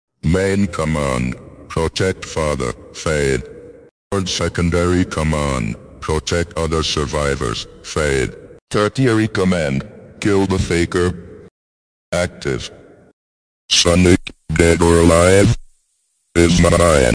main command metal sonic Meme Sound Effect